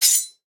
sword-unsheathe3.wav